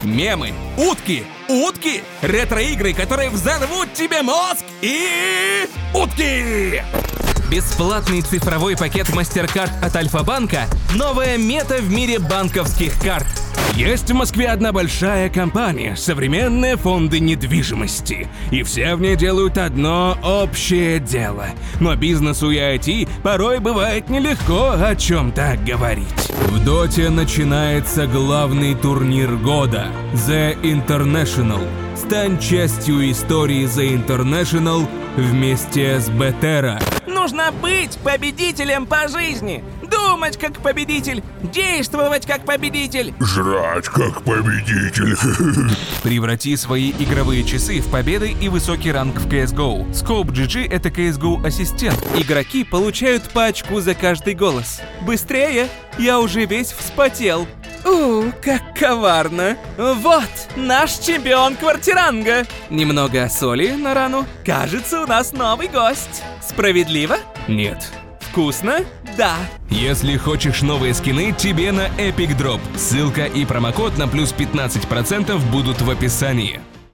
Муж, Пародия(Алхимик (Dota 2))